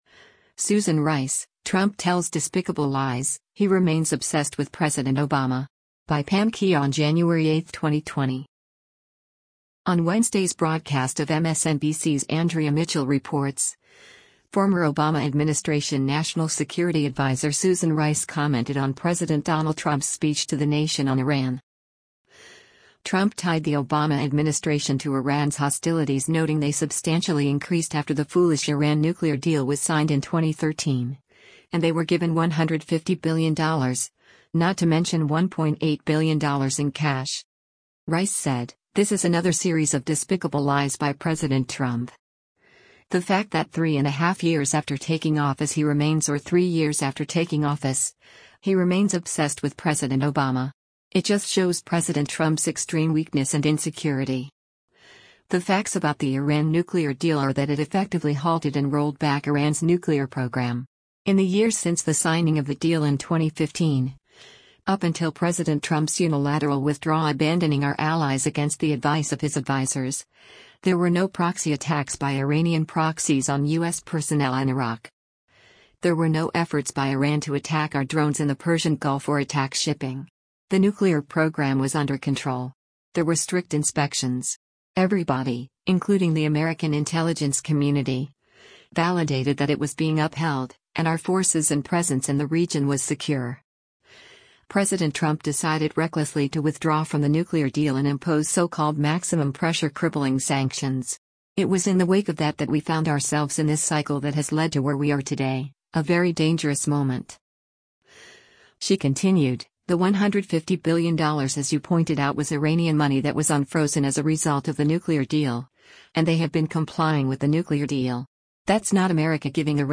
On Wednesday’s broadcast of MSNBC’s “Andrea Mitchell Reports,” former Obama administration National Security Advisor Susan Rice commented on President Donald Trump’s speech to the nation on Iran.